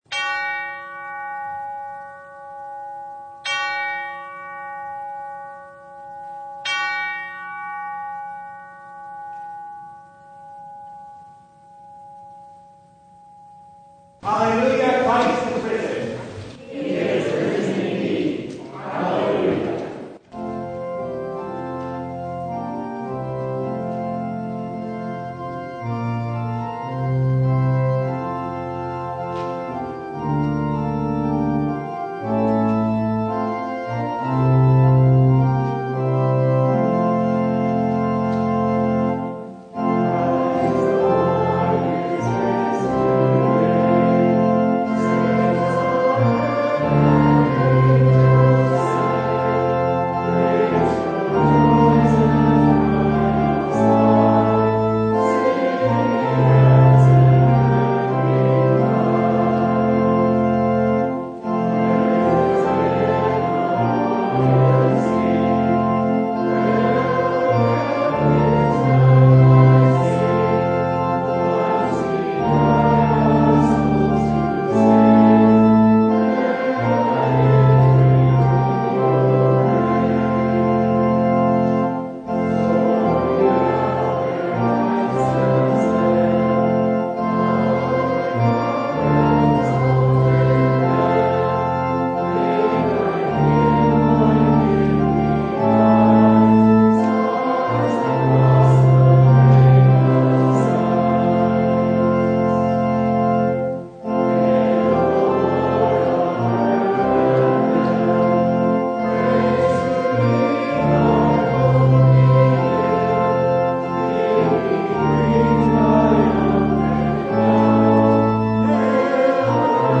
John 15:9-17 Service Type: Sunday “Love one another” sounds like it should be simple